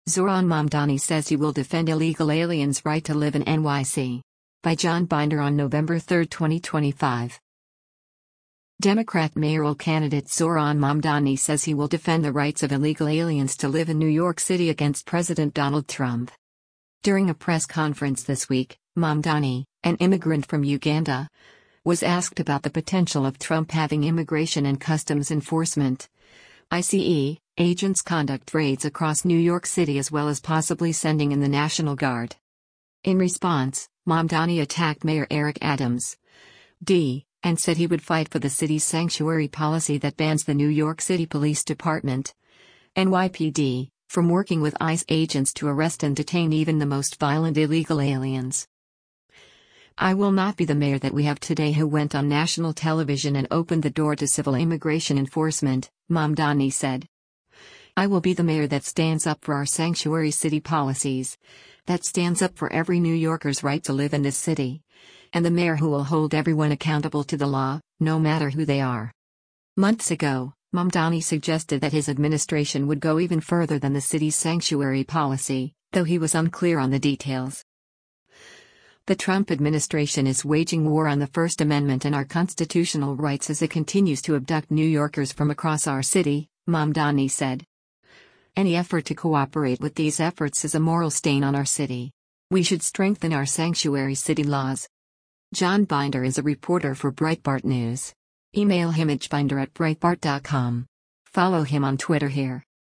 During a press conference this week, Mamdani, an immigrant from Uganda, was asked about the potential of Trump having Immigration and Customs Enforcement (ICE) agents conduct raids across New York City as well as possibly sending in the National Guard.